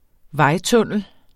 Udtale [ -ˌtɔnˀəl ]